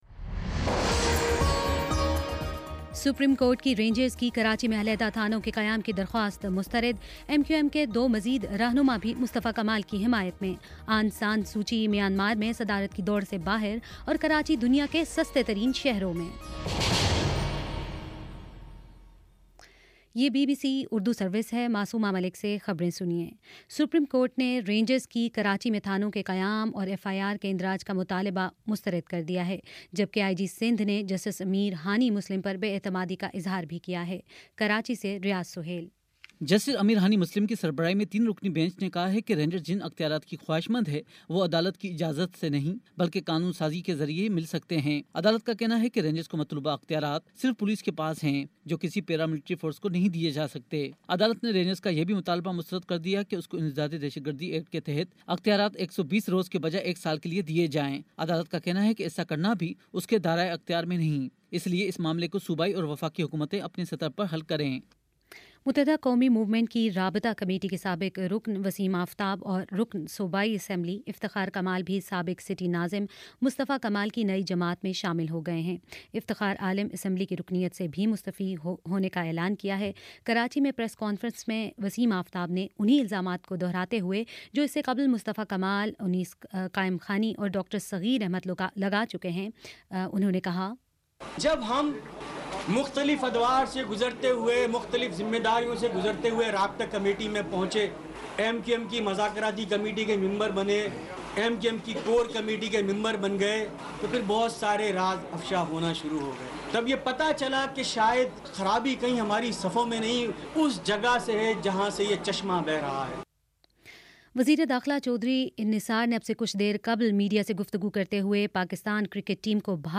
مارچ 10 : شام چھ بجے کا نیوز بُلیٹن